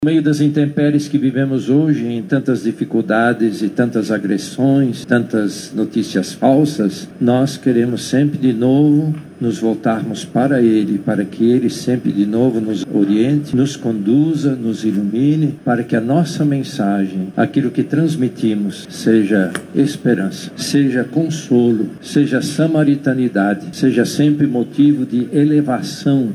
Durante a celebração, o cardeal Leonardo Steiner reforçou a missão dos comunicadores diante dos desafios atuais. Ele exortou os comunicadores, a serem sinais proféticos de esperança.